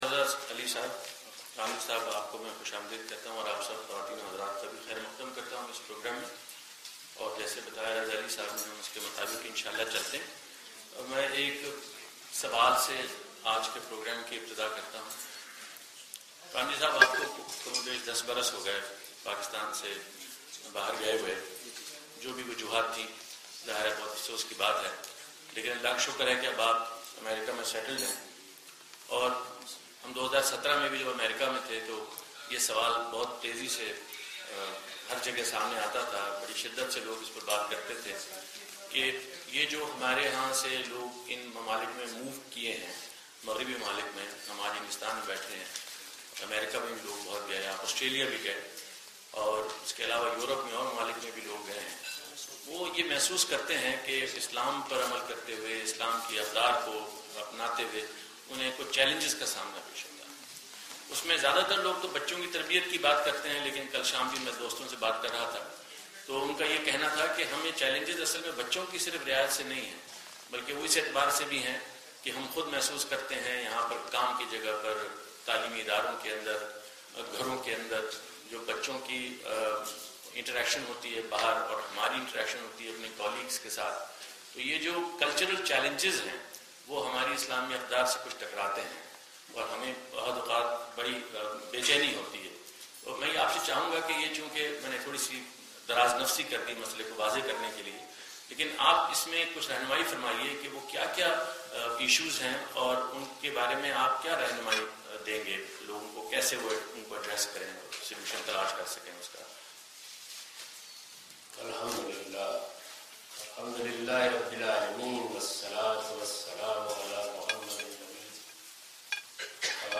On the 28th of February at Grand Sapphire Croydon